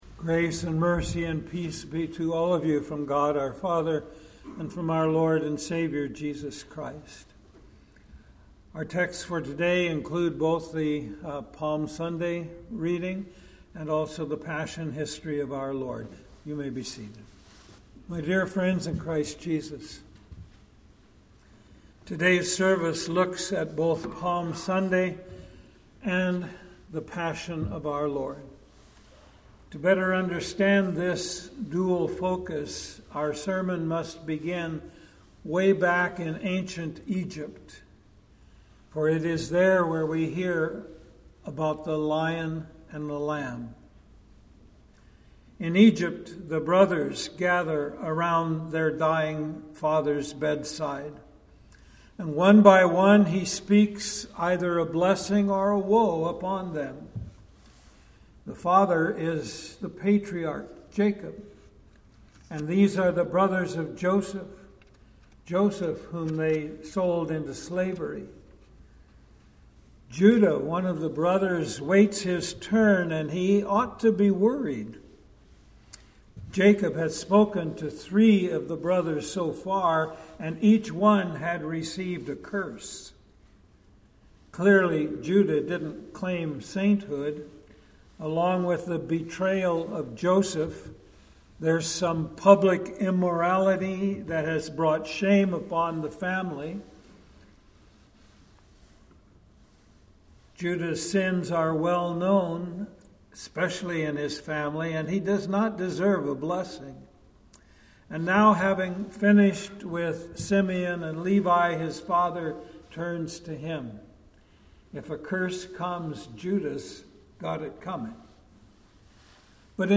Sermon-for-Sunday-March-29.mp3